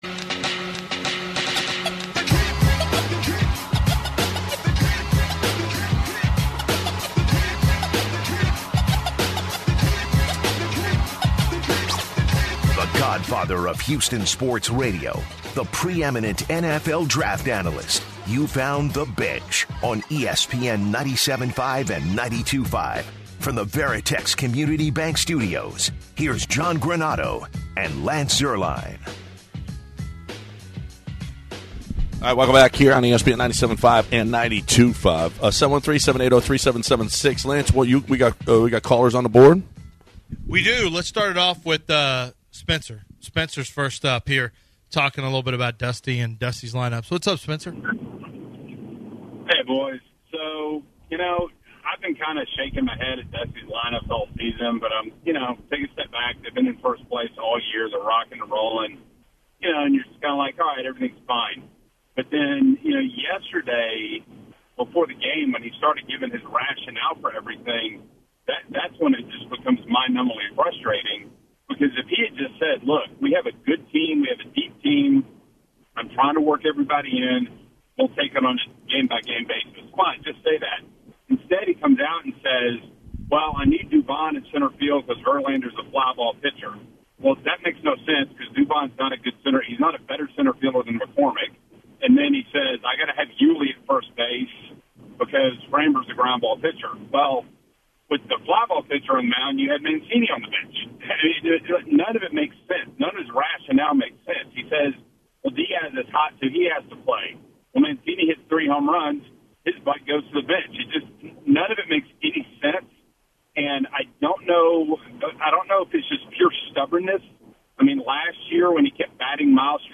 hit the phone lines to hear listeners complaints about Dusty Baker.